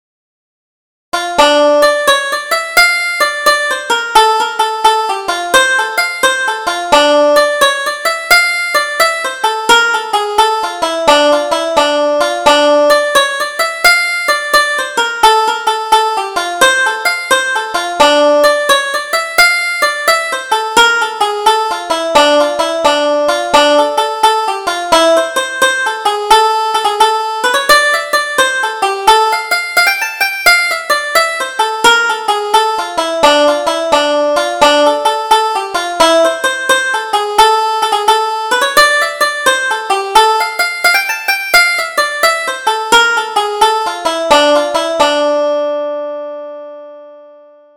Double Jig: Doctor O'Halloran